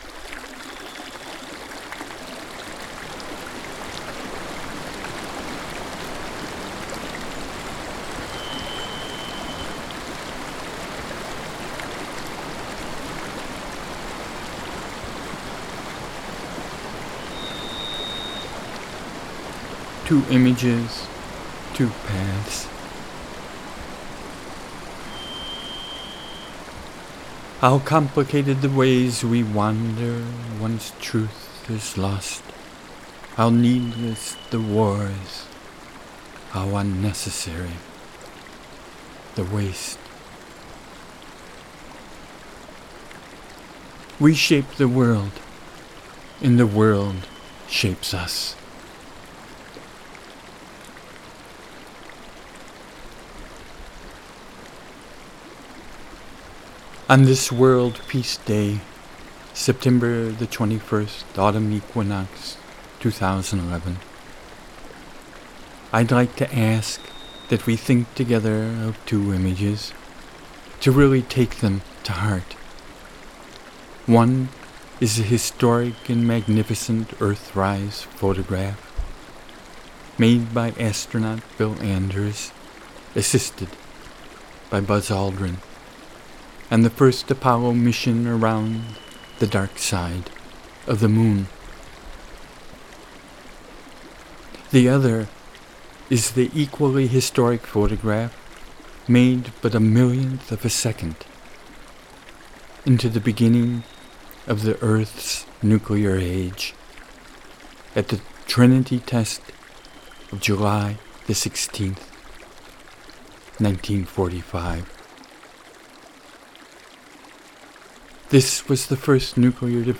[the birds heard in background are the SPIRIT THRUSH
(also know as Spirit Thrush (Zoothera naevia) & HERMIT THRUSH
(Catharus guttatus), both recorded here in the Wallowas